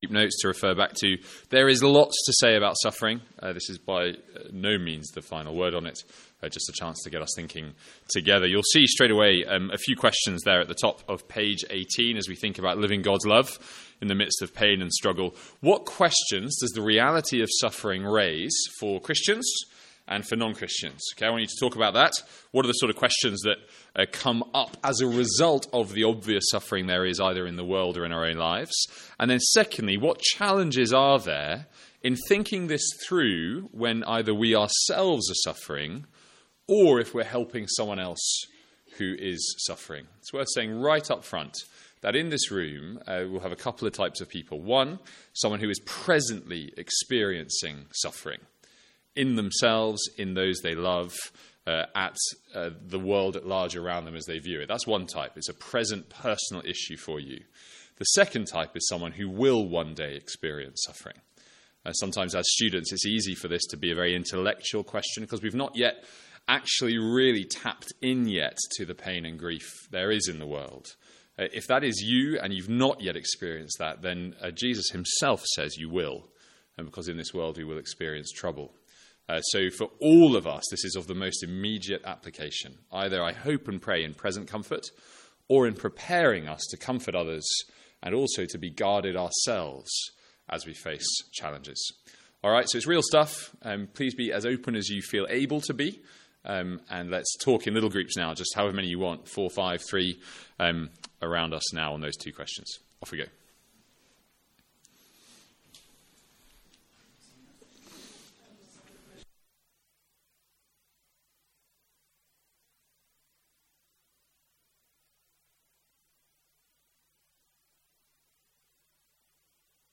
From our student Mid-Year Conference.